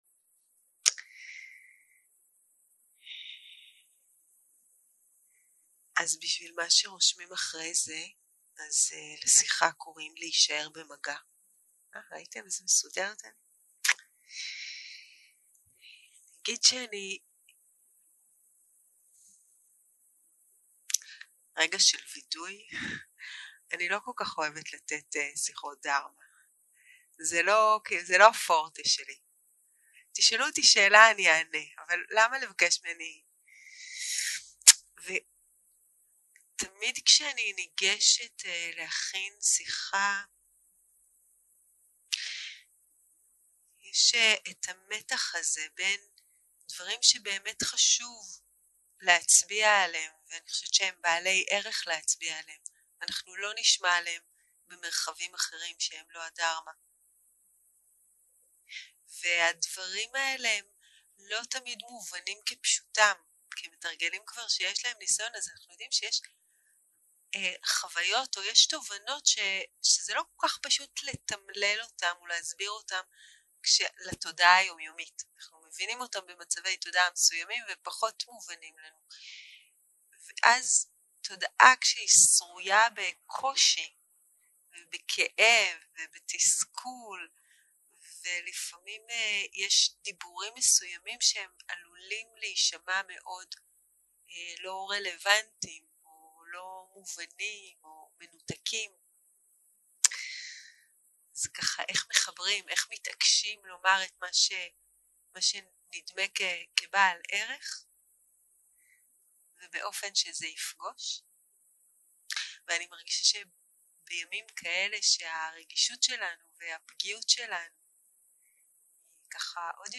Dharma type: Dharma Talks